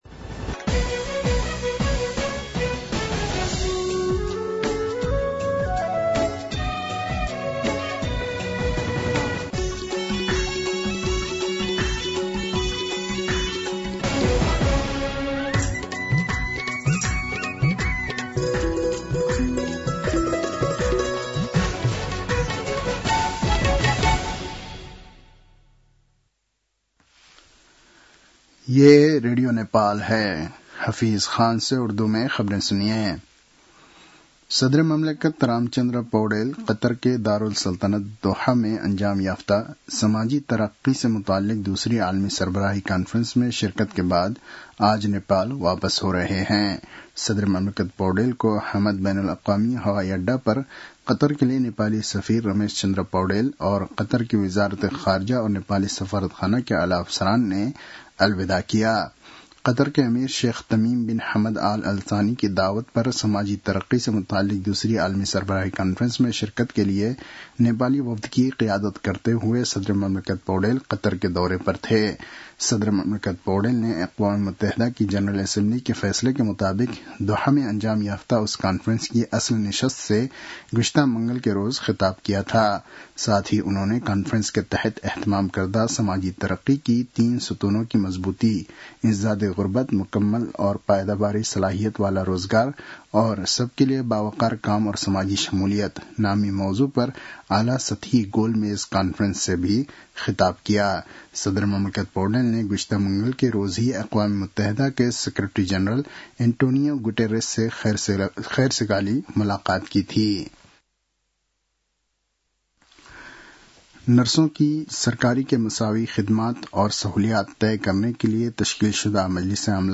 उर्दु भाषामा समाचार : २० कार्तिक , २०८२